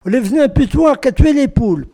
Enquête Arexcpo en Vendée
locutions vernaculaires